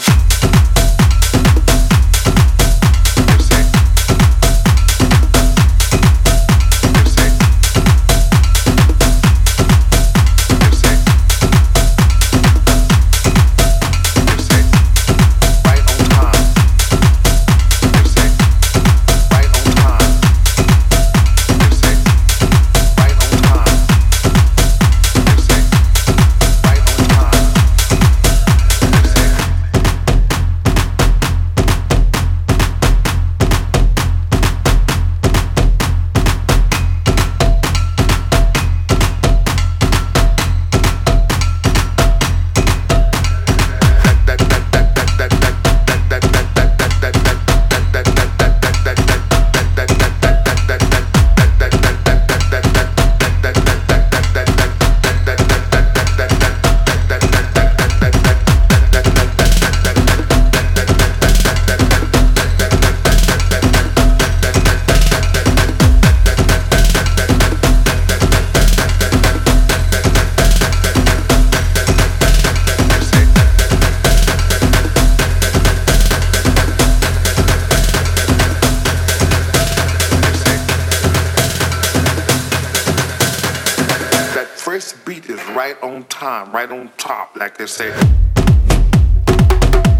パンチの効いたトライバル・ビートが躍動する